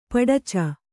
♪ paḍaca